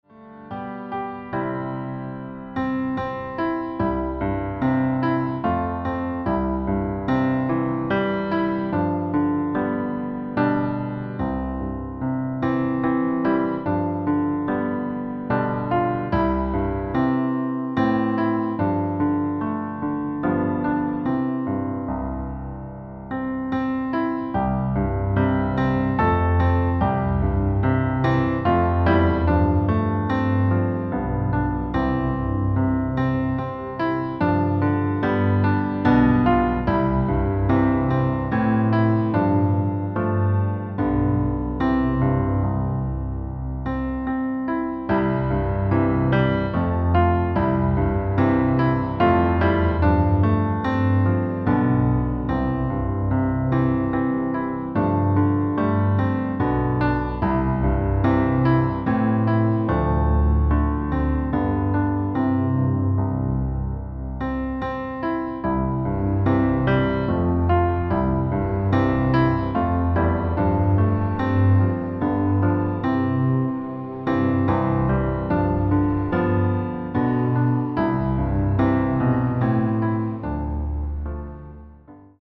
• Tonart: Bb Dur, C Dur , D Dur (Originaltonart )
• Art: Klavierversion mit Streichern
• Das Instrumental beinhaltet NICHT die Leadstimme